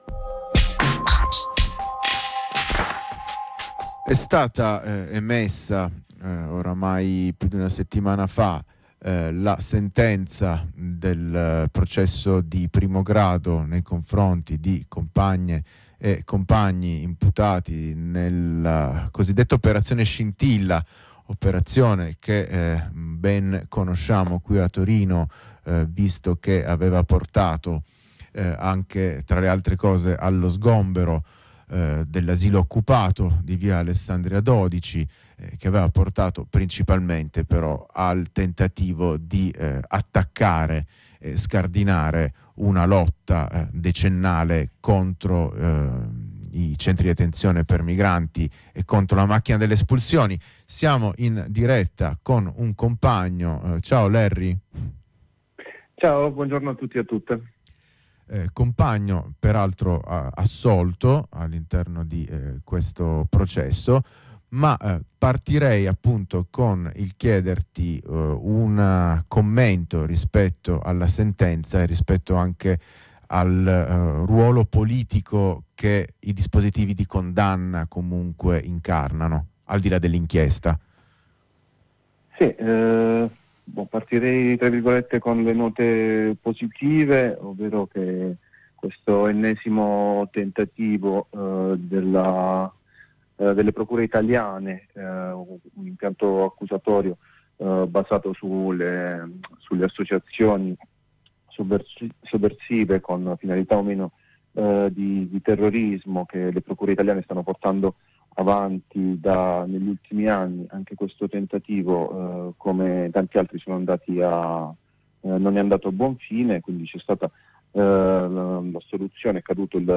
Un processo che ha visto l’impiego massiccio di intercettazioni, il tentativo di normalizzare prove antropometriche implausibili , l’attacco alla contro-informazione e alla solidarietà, lo sgombero di un’occupazione storica e conflittuale come l’ Asilo Occupato . Insieme a un compagno imputato, successivamente alla sentenza di primo grado, cerchiamo di analizzare il portato politico di questa inchiesta e dei dispotivi di condanna emanati.